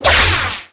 electro.wav